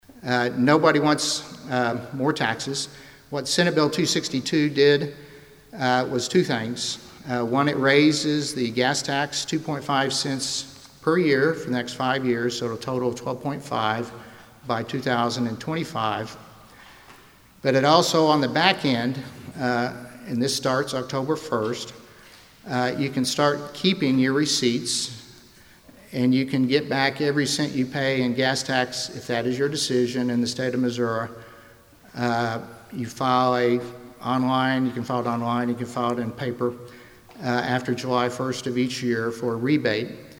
The West Plains Chamber of Commerce met Thursday for their regular monthly meeting and the guest speakers were State Representative David Evans and State Senator Karla Eslinger.